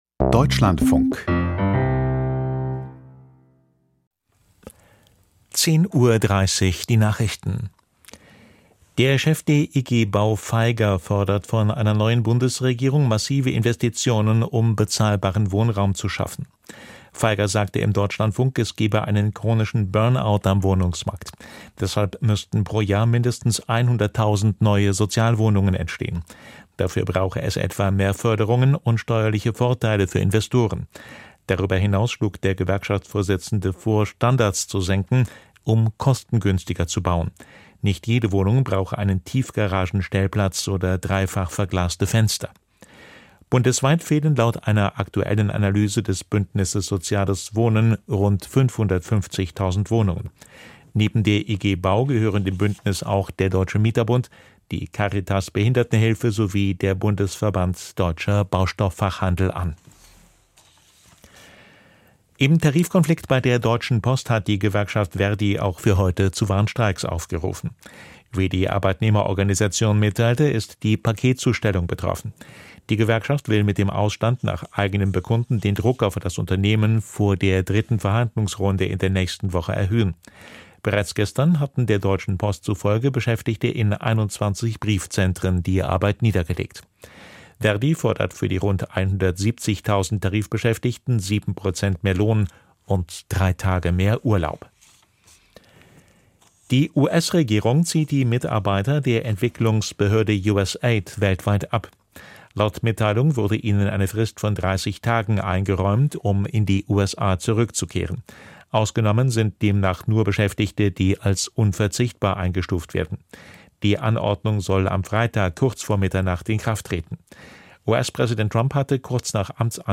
Die Deutschlandfunk-Nachrichten vom 05.02.2025, 10:30 Uhr